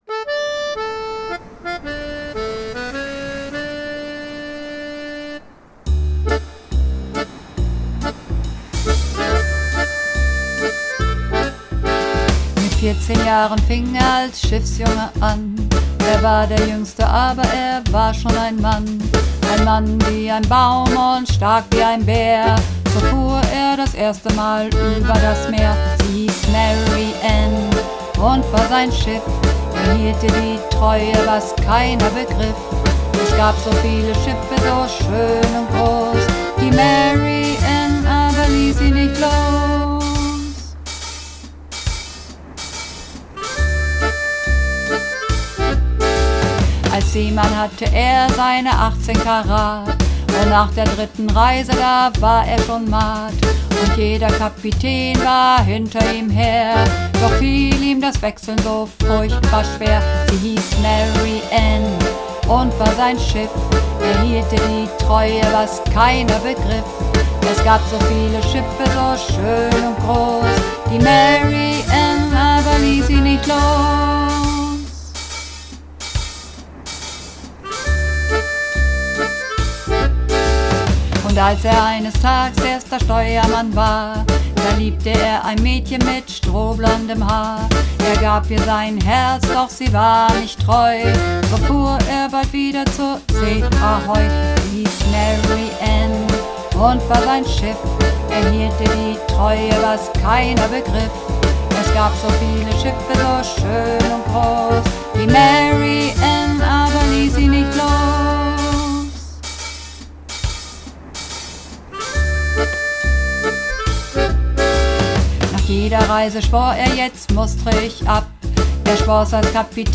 Übungsaufnahmen - Sie hiess Mary Ann
Sie hiess Mary Ann (Tief)